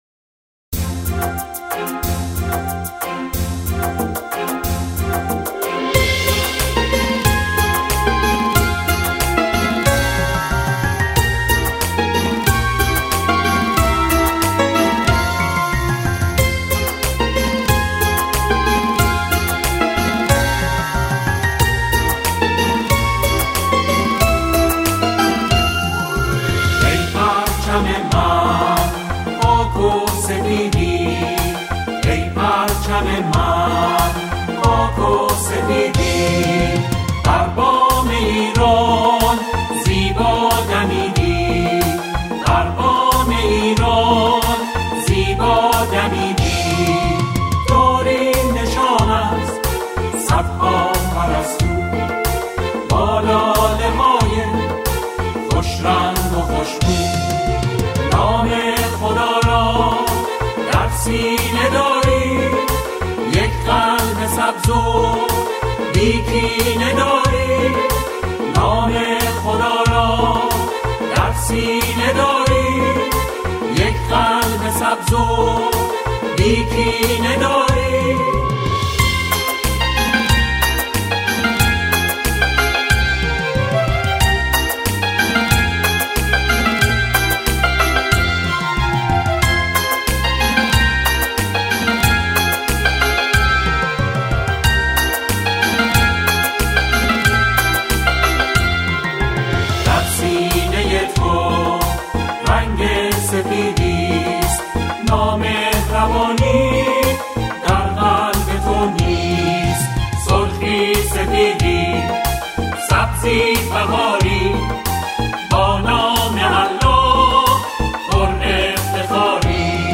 همخوانان این اثر، اعضای گروه کر هستند.